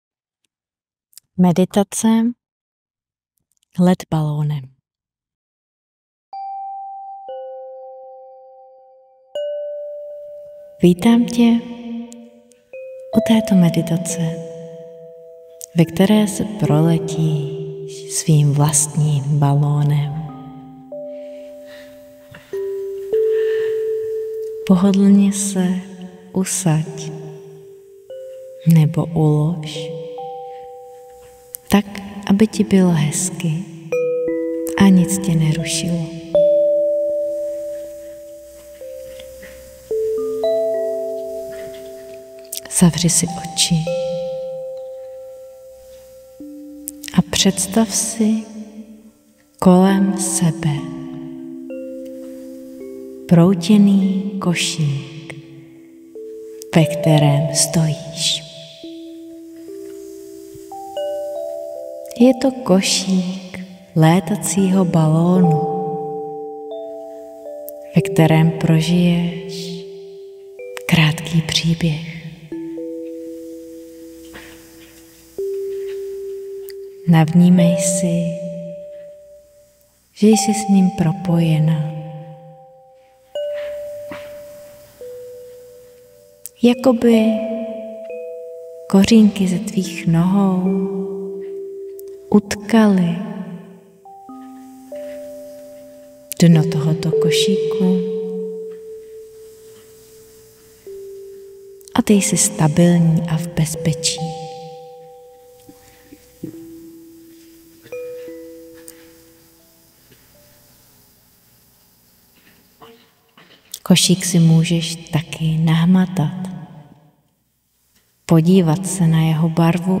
Meditace let balónem